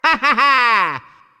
One of Luigi's voice clips in Mario Party 6